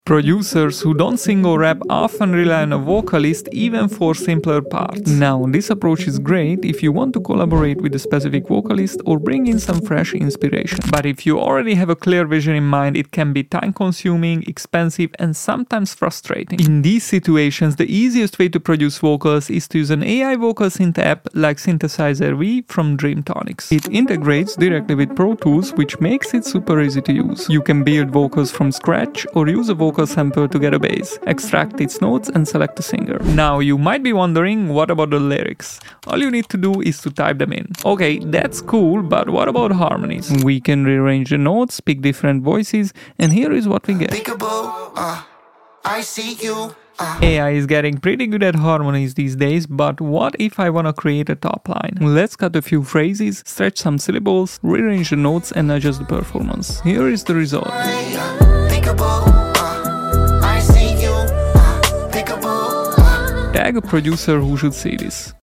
🎤 Meet Synthesizer V Studio 2 by Dreamtonics – the AI vocal synth that sounds unreal.